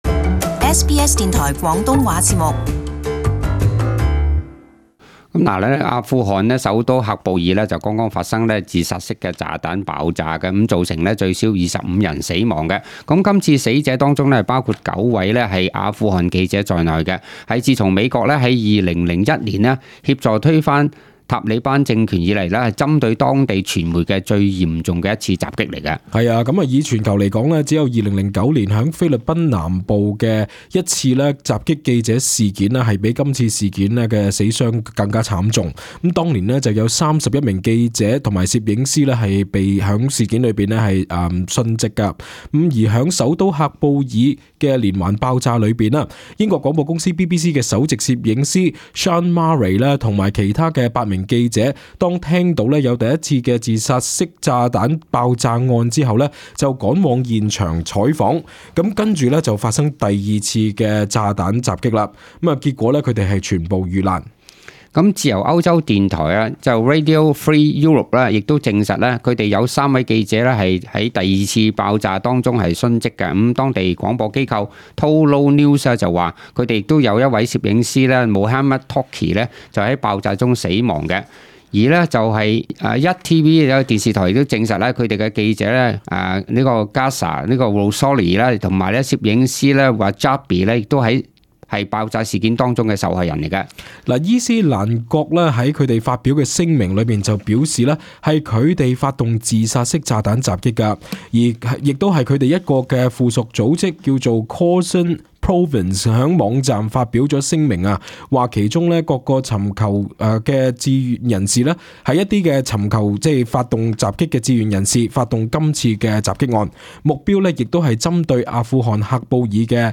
【時事報導】阿富汗首都發生連環自殺式炸彈襲擊